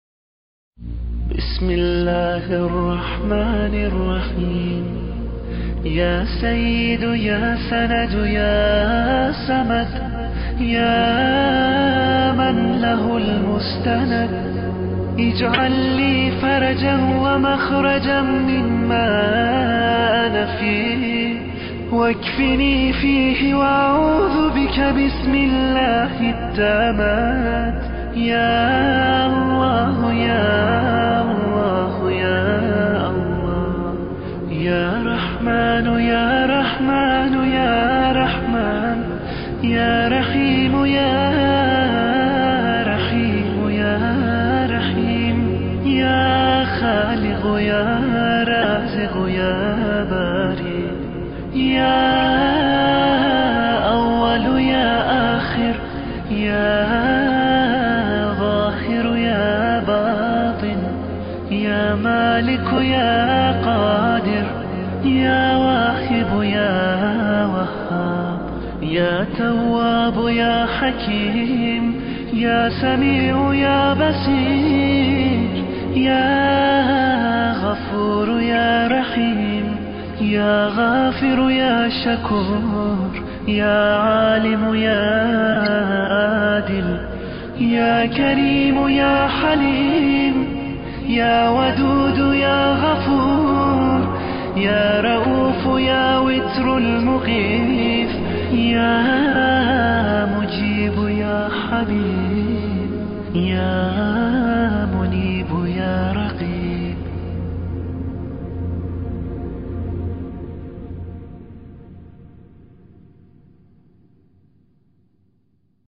• نوحه و مداحی
برای شما همراهان عزیز کامل ترین مجموعه دعای معراج با نوای مداحان مشهور را با متن و ترجمه فارسی آماده کرده ایم…